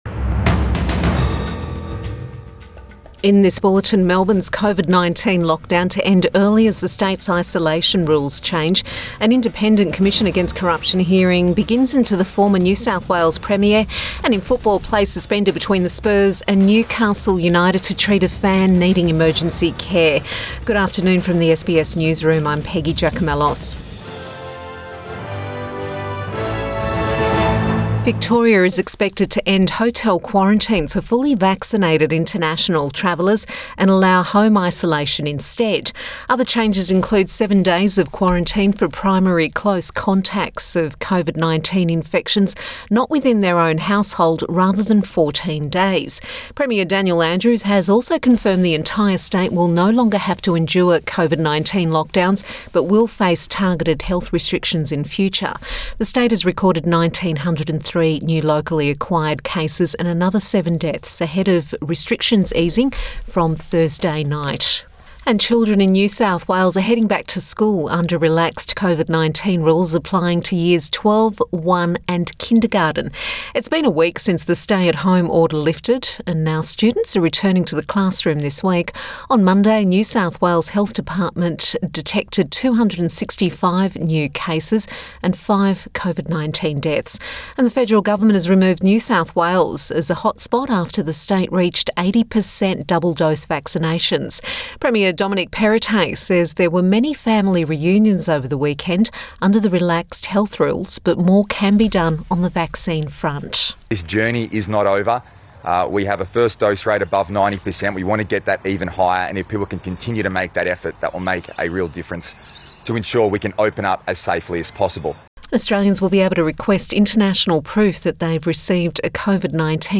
Midday bulletin October 18 2021